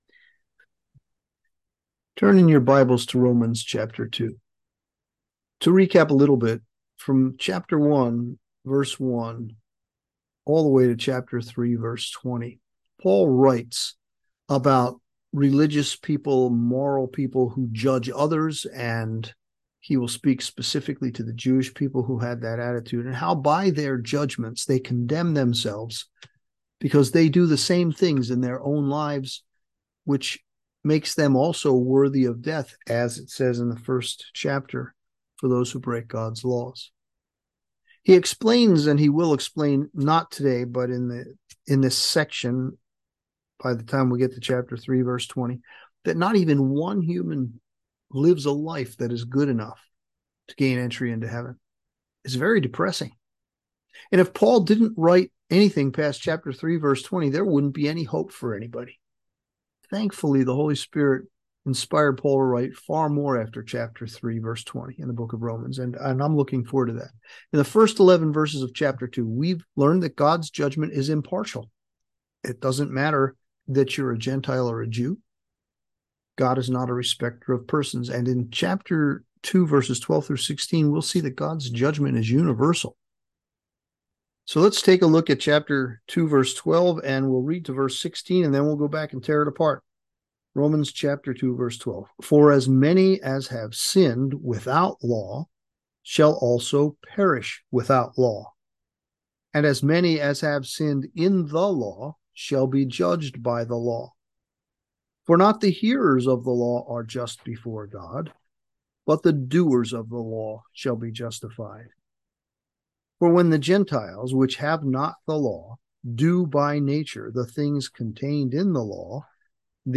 Sermons | Calvary Chapel on the King's Highway